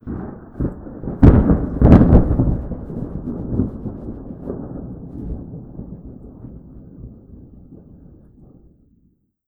tenkoku_thunder_medium04.wav